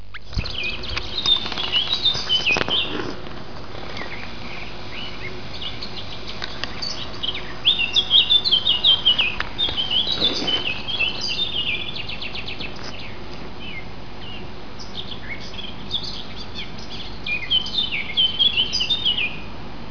Black Forest Hike
bird songs (20 seconds).
birds.wav